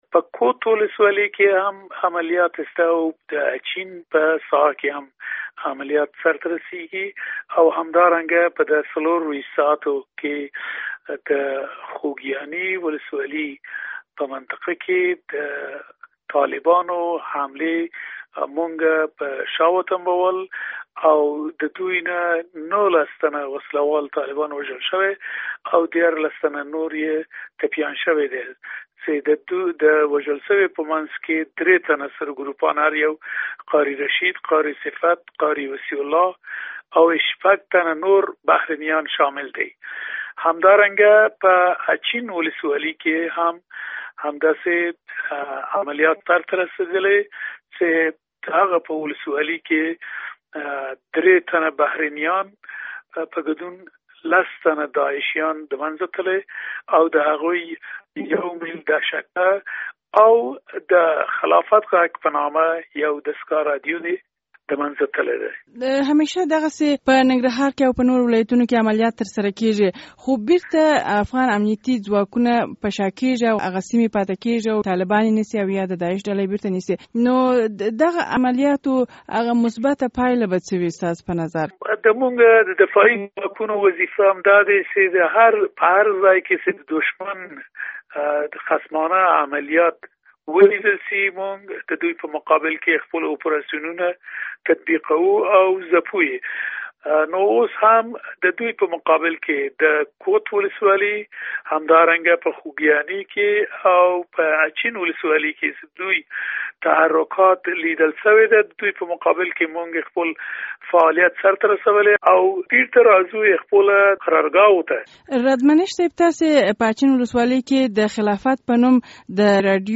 مرکه
له ښاغلي رادمنش سره مرکه